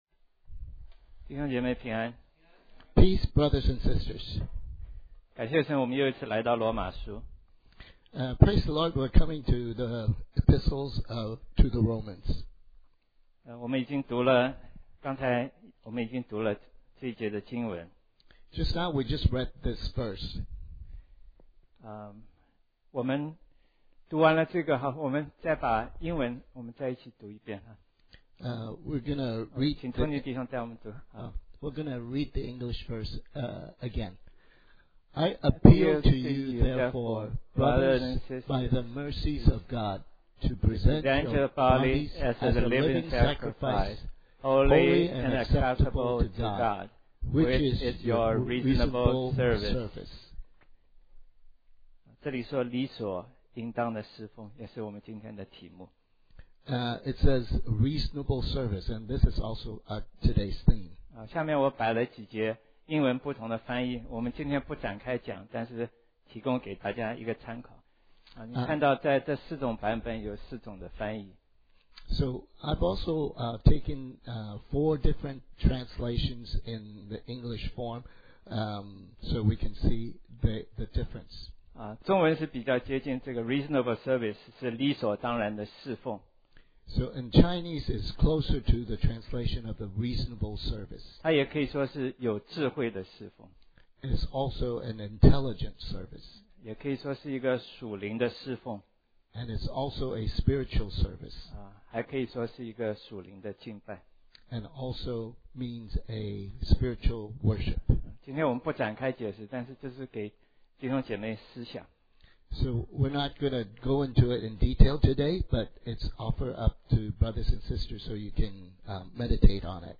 Chinese Sermon